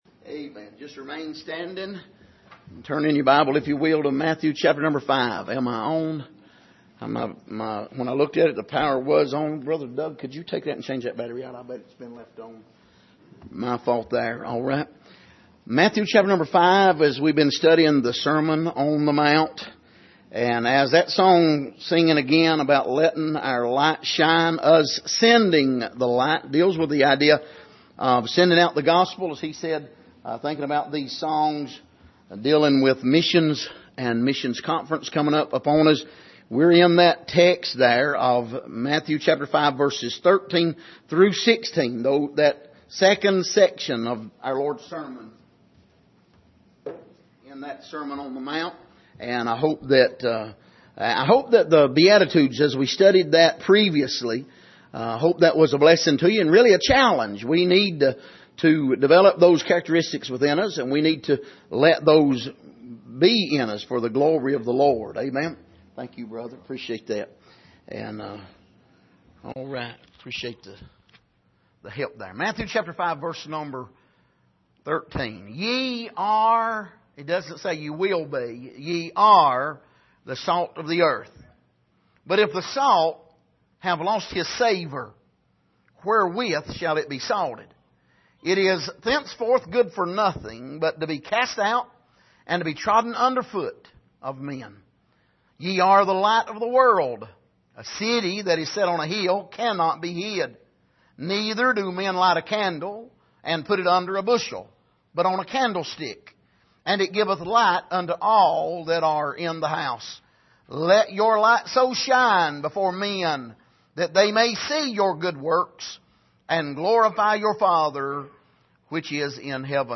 Passage: Matthew 5:13-16 Service: Sunday Morning